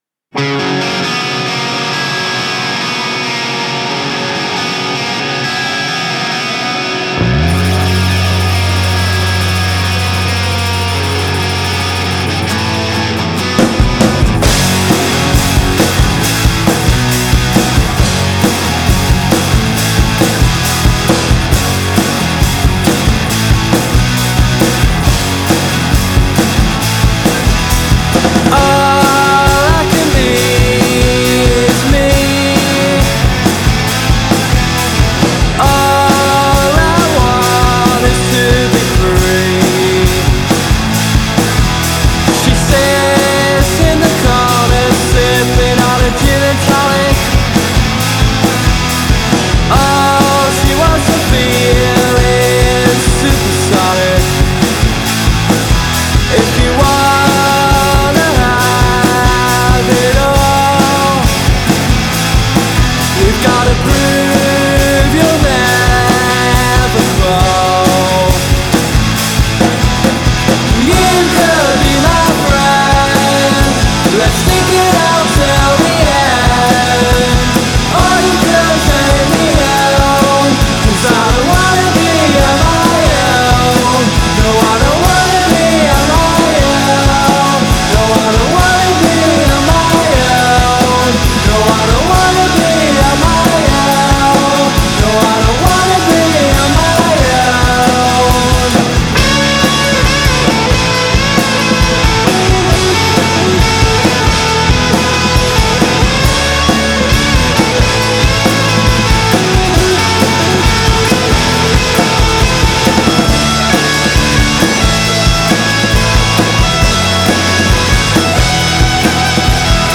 an indie rock and roll band that consists of five members
drums
bass guitar
rhythm guitar